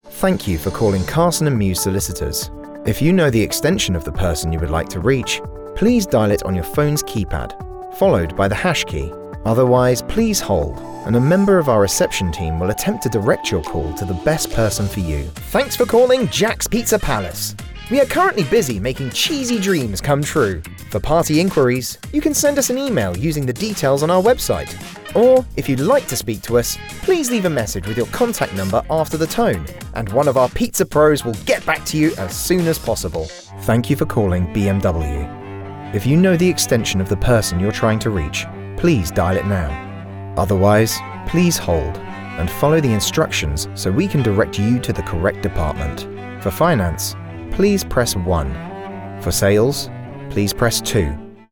Engels (Brits)
Commercieel, Veelzijdig, Vriendelijk, Natuurlijk, Warm
Telefonie